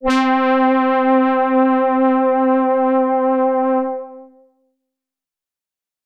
Lush Pad C5.wav